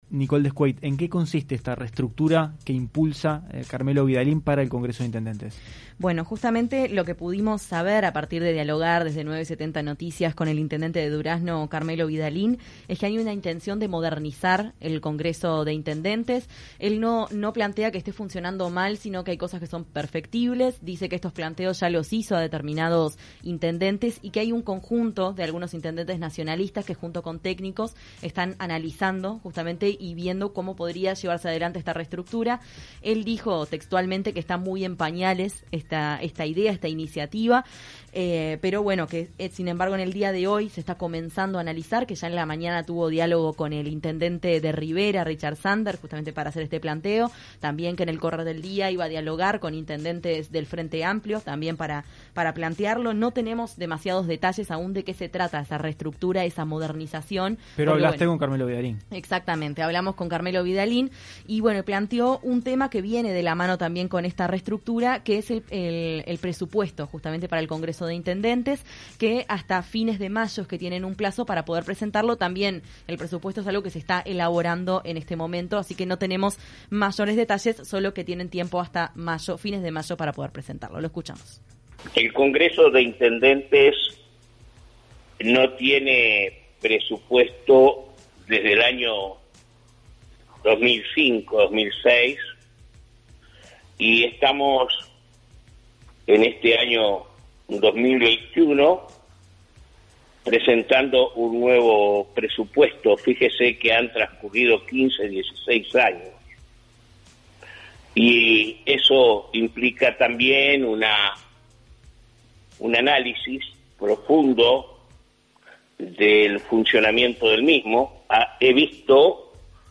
Informe especial
con la palabra de los jerarcas de Durazno, Carmelo Vidalín, y de Florida, Guillermo López.